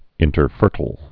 (ĭntər-fûrtl)